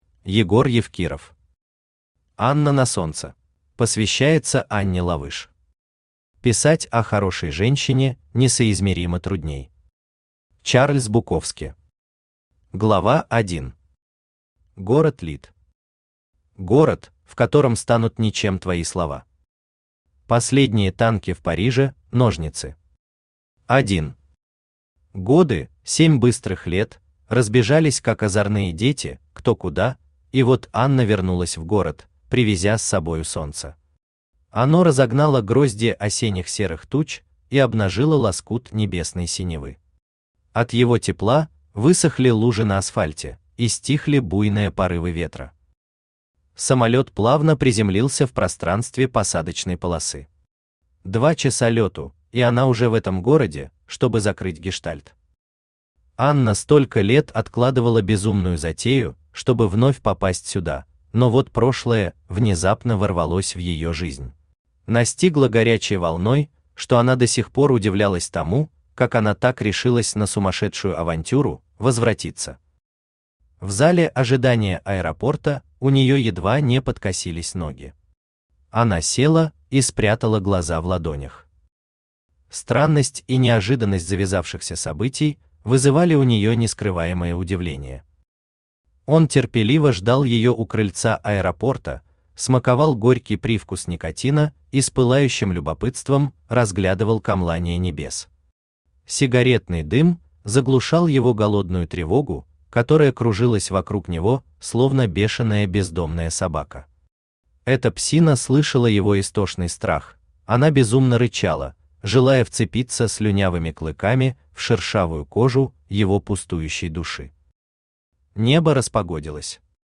Аудиокнига Анна на Солнце | Библиотека аудиокниг
Aудиокнига Анна на Солнце Автор Егор Евкиров Читает аудиокнигу Авточтец ЛитРес.